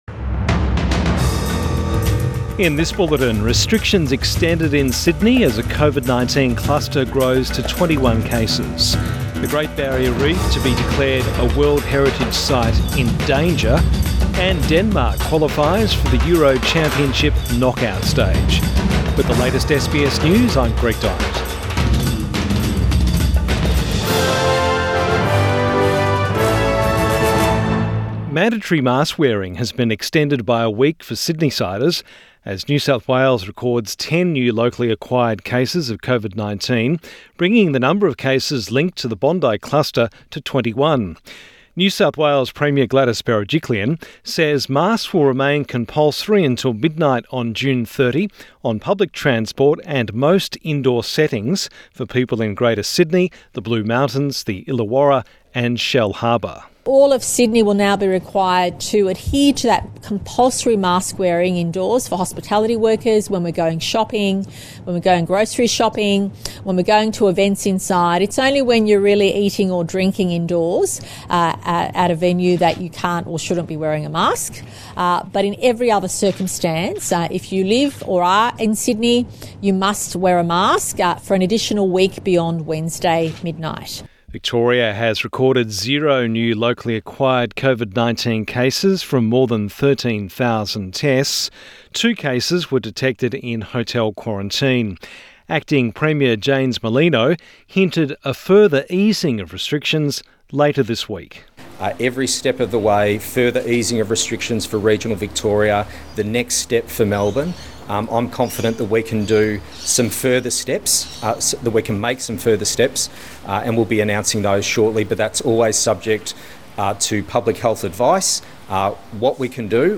PM bulletin 22 June 2021